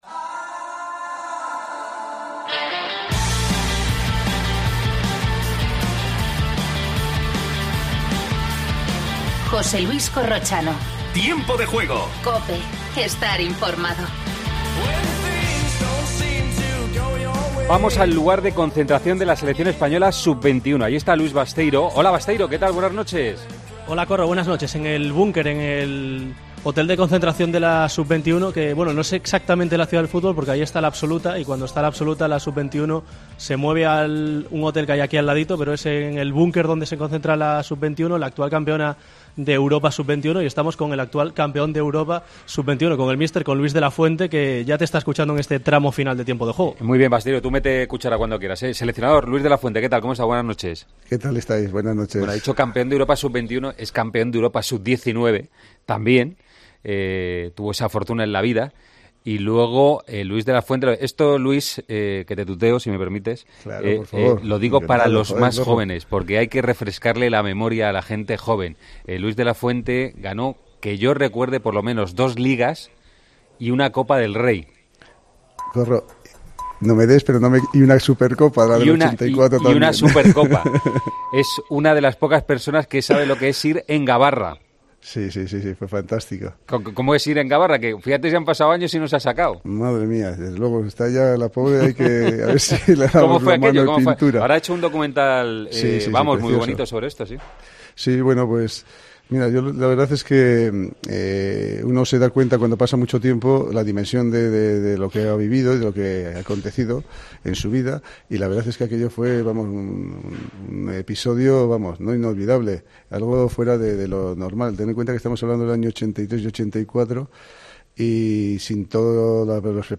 AUDIO: Entrevista a Luis de la Fuente, seleccionador sub-21. Rafa Nadal ya está en Madrid. Final de la Copa Masters.